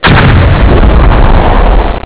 death4.wav